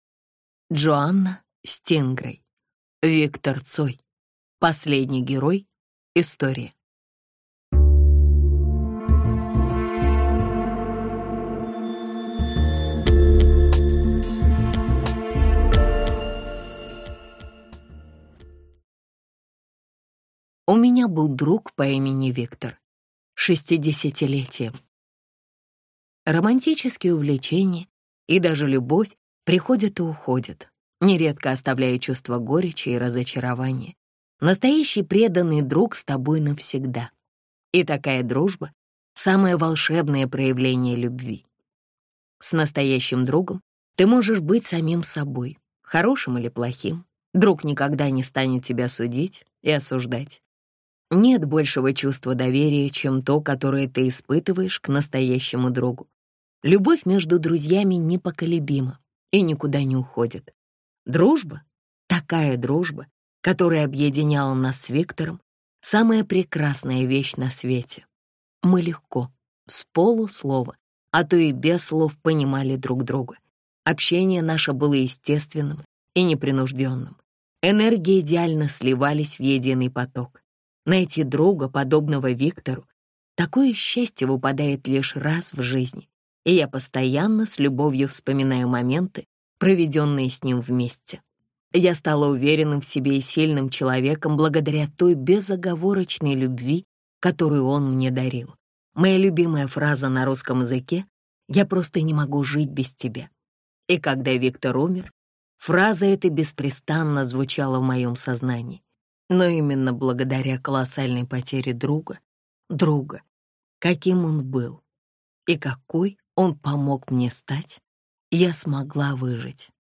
Аудиокнига Виктор Цой. Последний герой. История | Библиотека аудиокниг